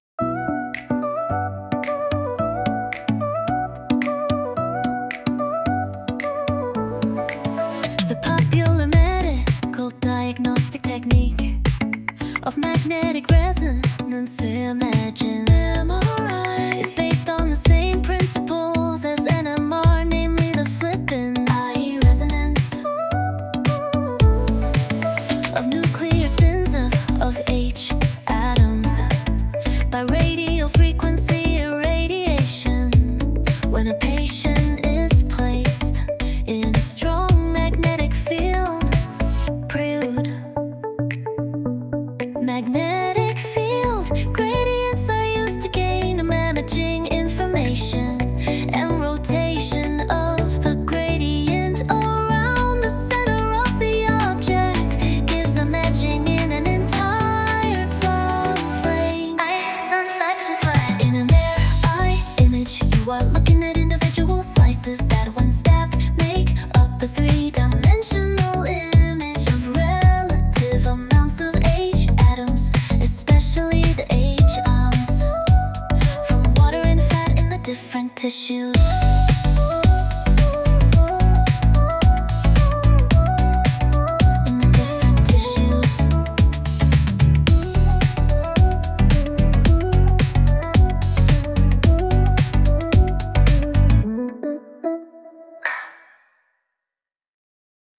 MRI paragraph as a Kpop song
MRI Kpop.mp3